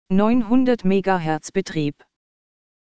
Anbei fehlende Systemsounds.